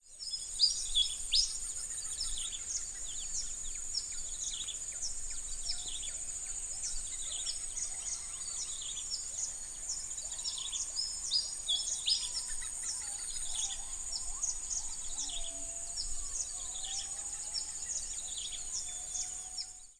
The dawn chorus
The audio clip is from a recording in secondary forest habitat. The bird calls featured include the following species: Mixornis gularis, Prinia flaviventris, Anthracoceros albirostris, Pericrocotus flammeus
Secondary-forest-sample.mp3